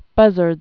(bŭzərdz)